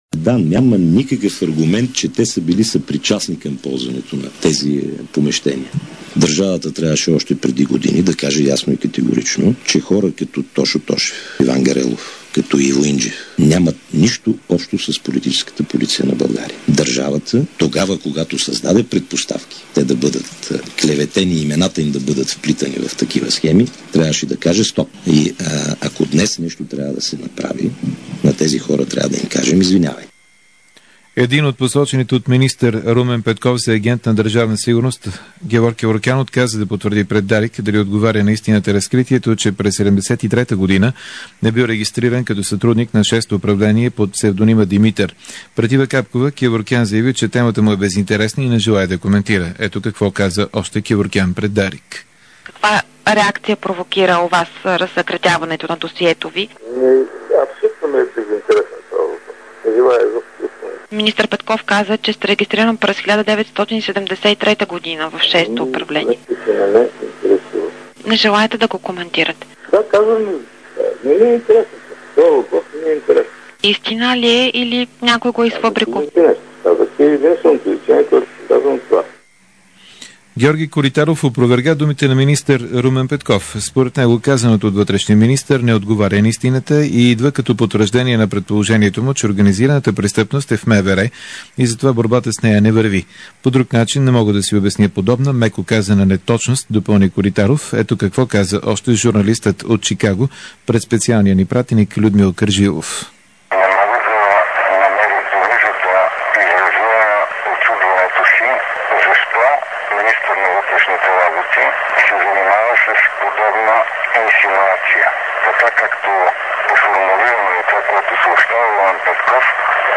DarikNews audio: Обзорна информационна емисия 20.05.2006